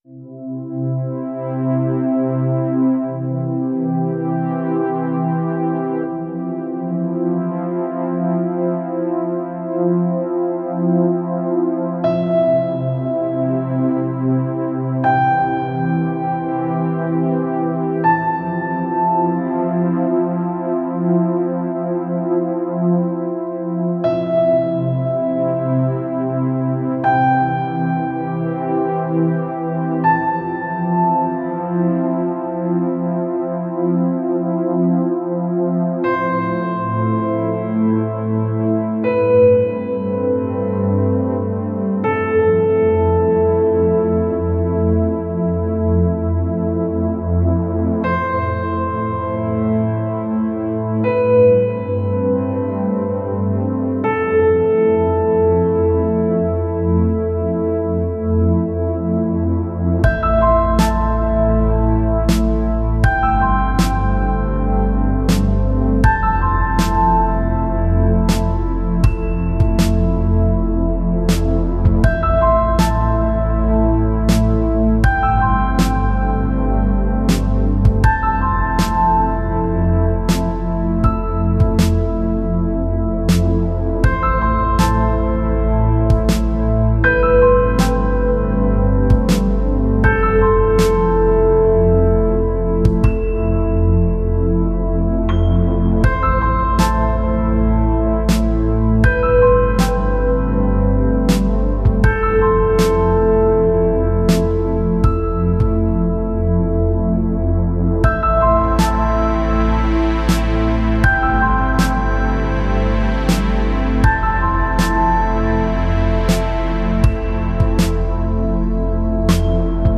دانلود اهنگ بی کلام و ملایم برای پس زمینه کلیپ